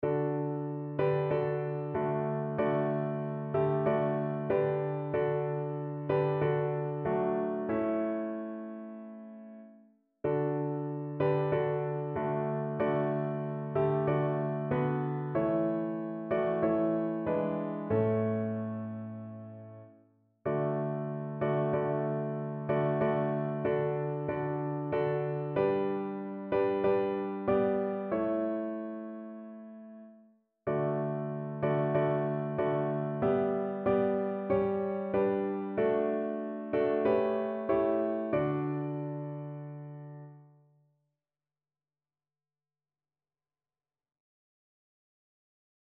Durchhilfe auf dem Weg Evangeliumslieder Liebe und Güte Gottes
Notensatz (4 Stimmen gemischt)